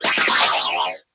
Cri de Dunaja dans Pokémon HOME.